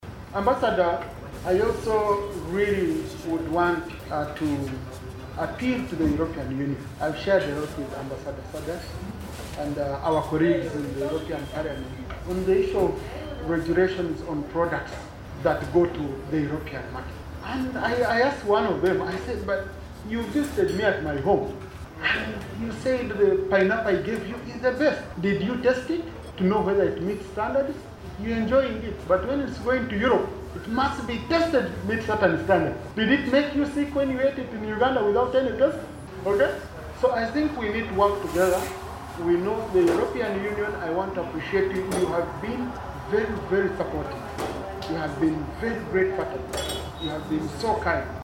Deputy Speaker Tayebwa addresses the farmers and other guests at DFCU offices
AUDIO Deputy Speaker Tayebwa